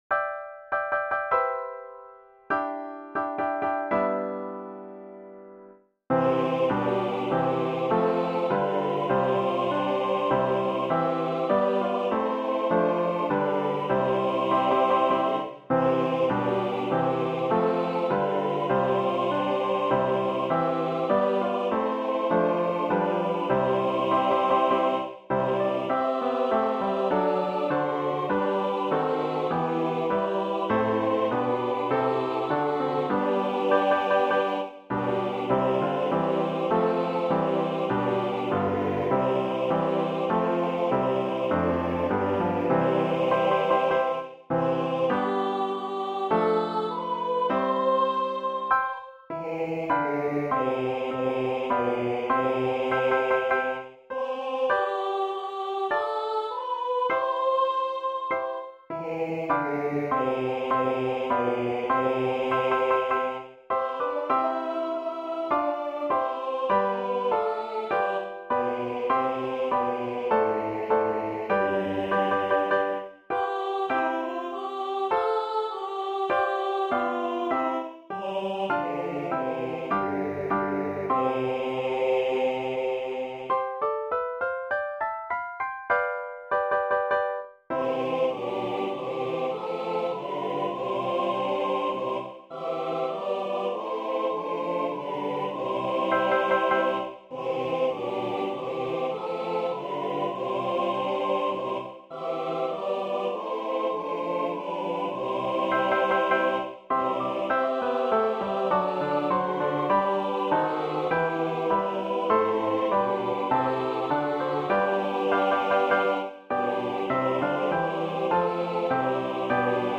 There is a A Cappella section included.
Voicing/Instrumentation: SATB We also have other 8 arrangements of " All Glory, Laud, and Honour ".